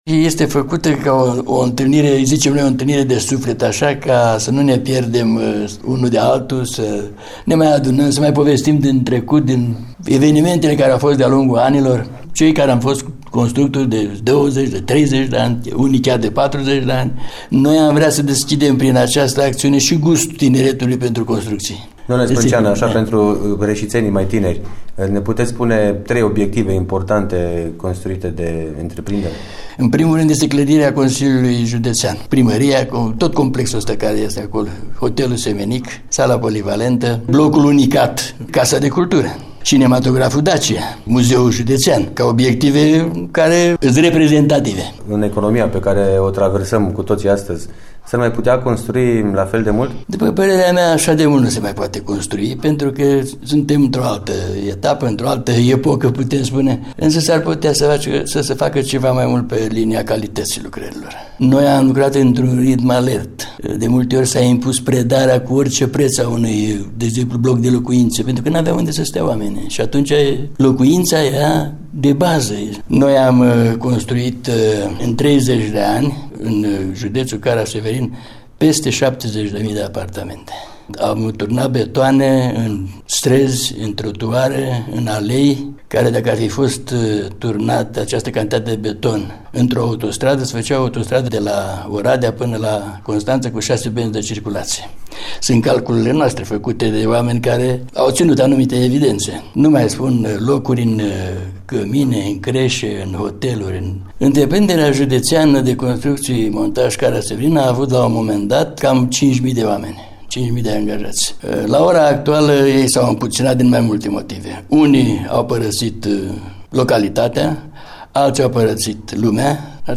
discuţie